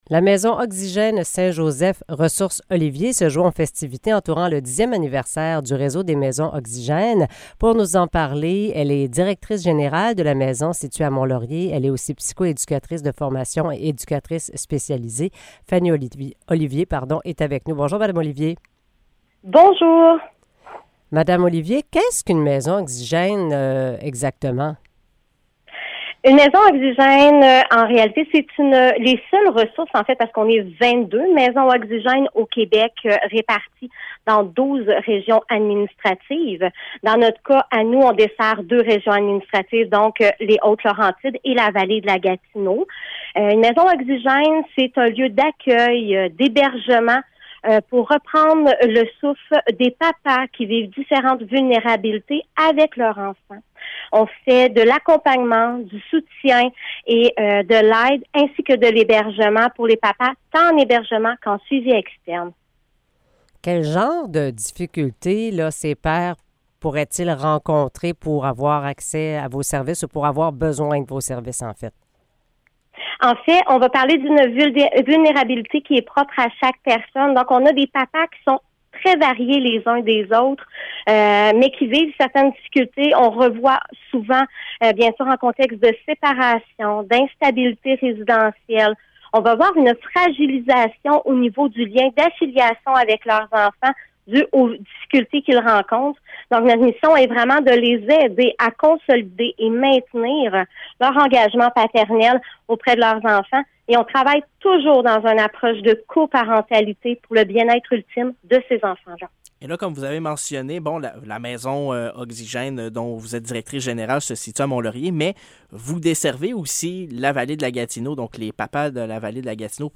Entrevue pour les 10 ans des Maisons Oxygène